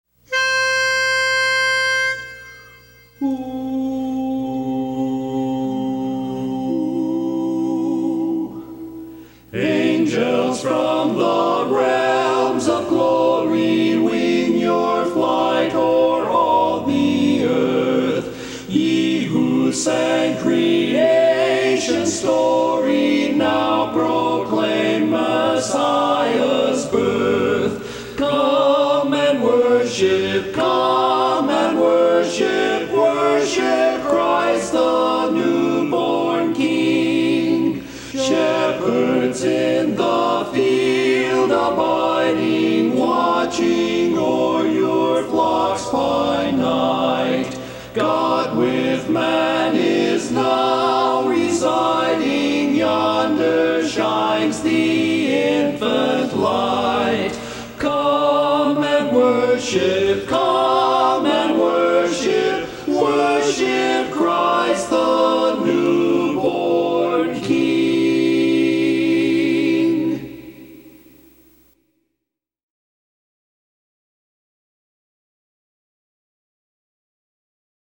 Barbershop